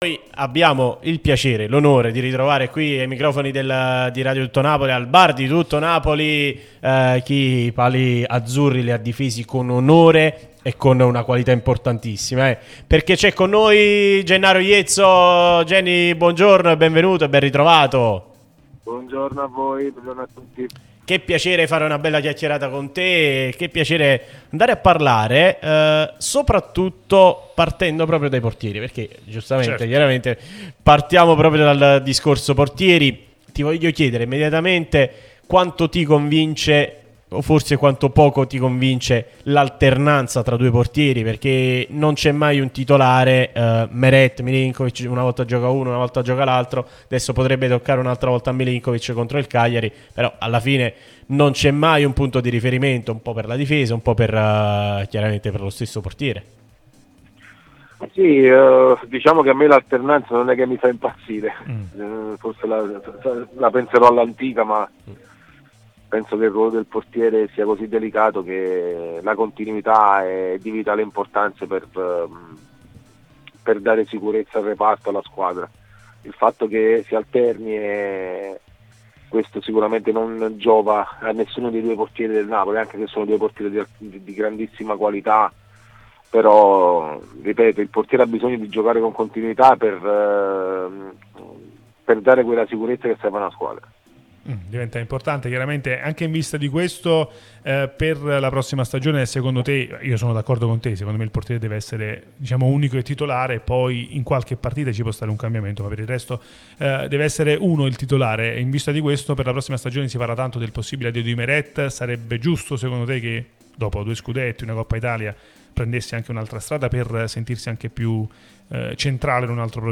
l'unica radio tutta azzurra e sempre live